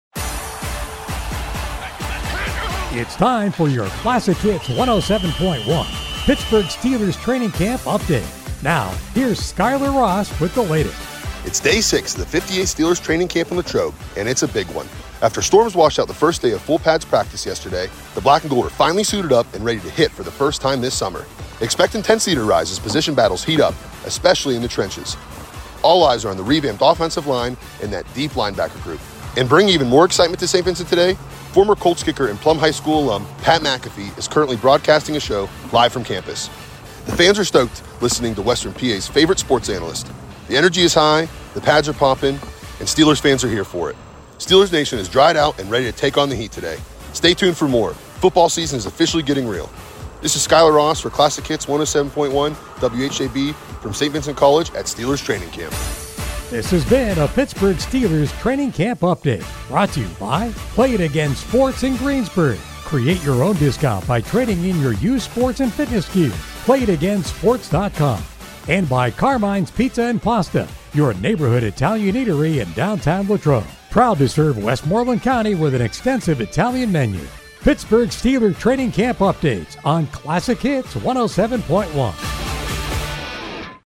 Our Greensburg affiliate WHJB is at Steelers Training Camp in Latrobe and filing three reports daily on the day’s activities and more.
July 30 – Report 1